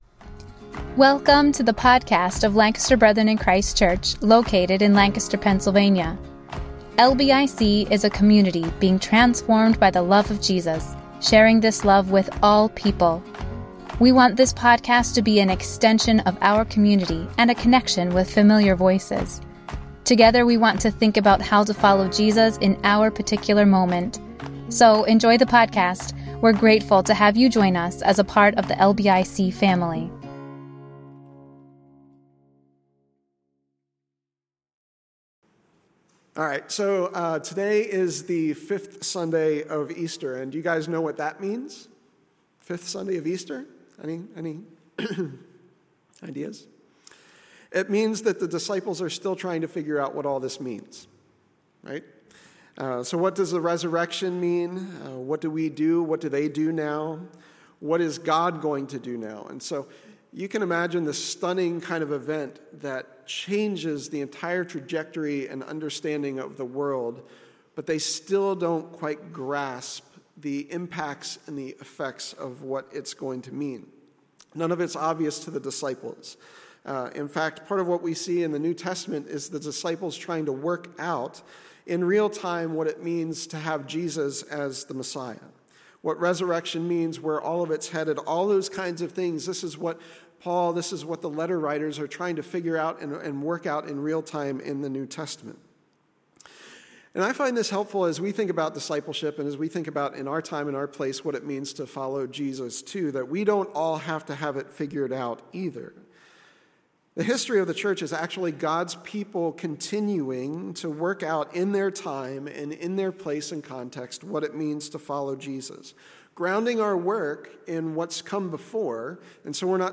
5th Sunday of Eastertide: Glorying in Service Message